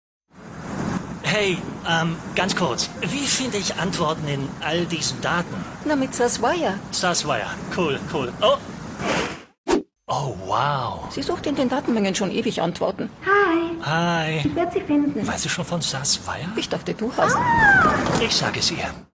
Male
Assured, Character, Cheeky, Confident, Cool, Engaging, Friendly, Natural, Smooth, Witty, Versatile, Authoritative, Corporate, Warm
Microphone: Neumann U87